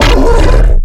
giant_hurt_1.ogg